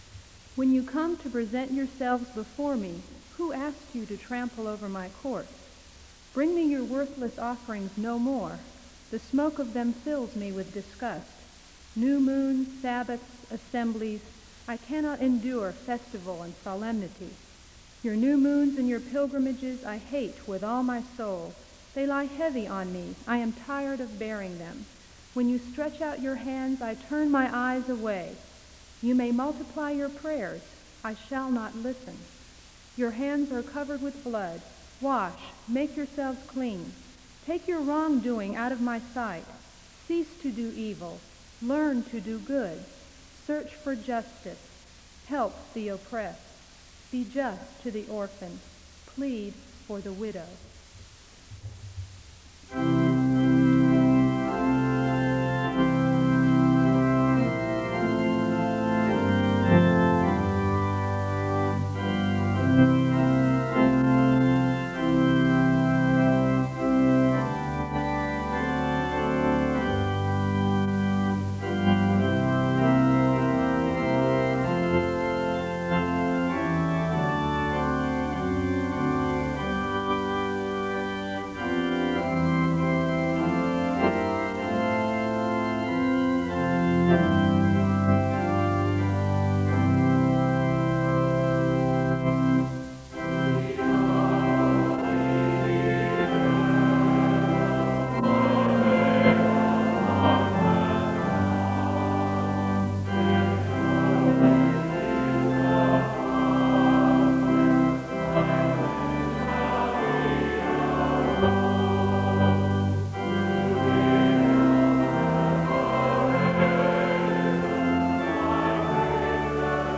Service is opened with a special reading (0:00-0:51). The choir leads in song (0:51-3:45). Scripture is read aloud from John 5:1-18 (3:45-5:55).
The choir leads in another song (7:00-9:15).